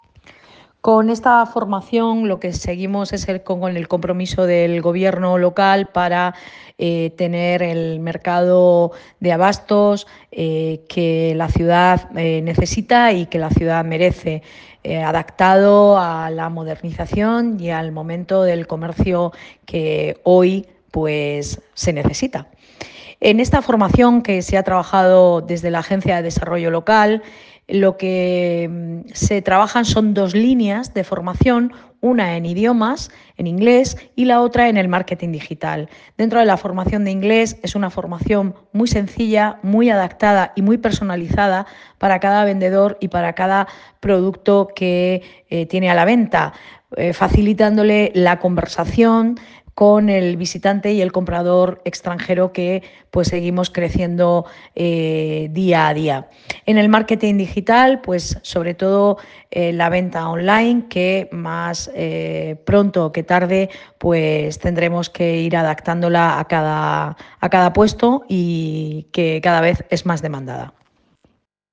Enlace a Declaraciones de la edil de Comercio, Belén Romero.